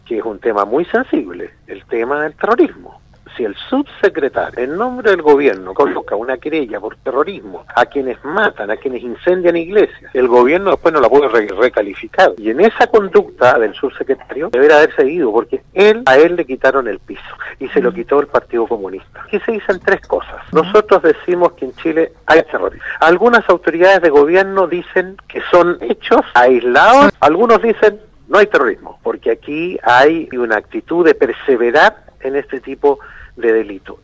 Si “el Gobierno y el partido comunista le quitaron el piso al Subsecretario Aleuy, el debió haberse ido”, dijo el senador Iván Moreira en conversación con radio Sago.